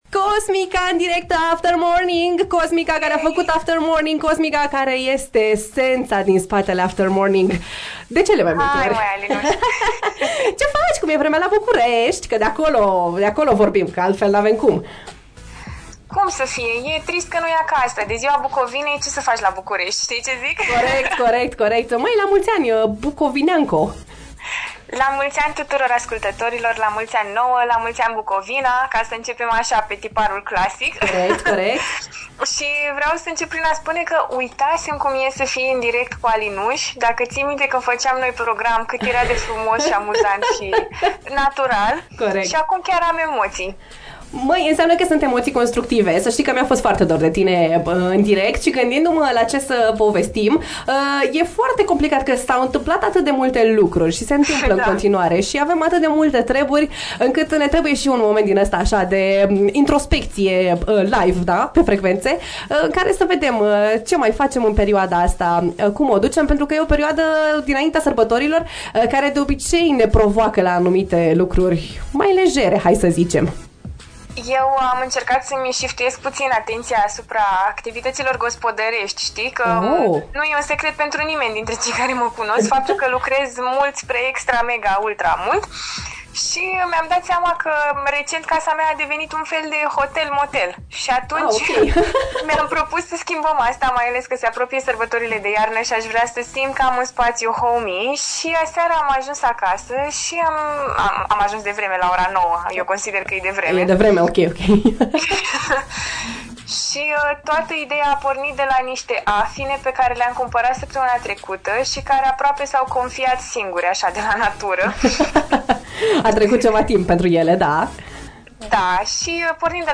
Banana bread & târguri de Crăciun, live la AFTER MORNING